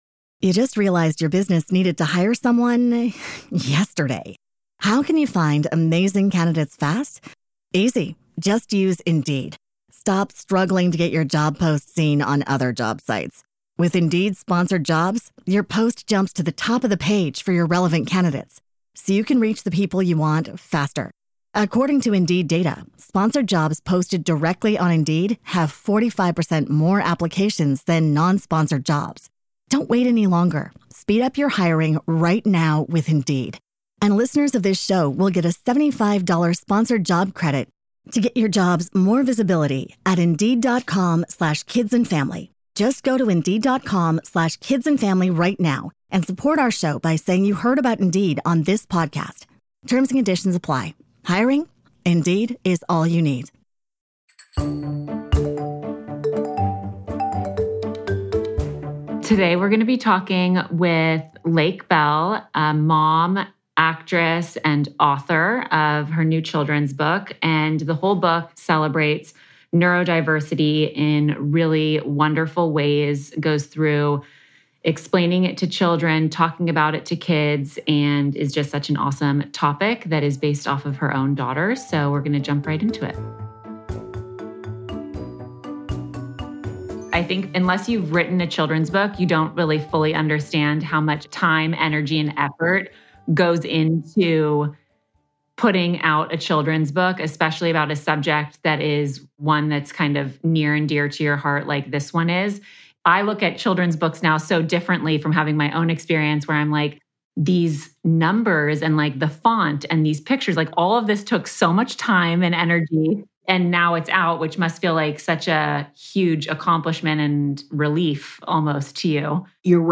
In this heartfelt episode, I sit down with actress, director, and author Lake Bell to talk about her deeply personal journey as a mother, and the inspiration behind her new children’s book All About Brains.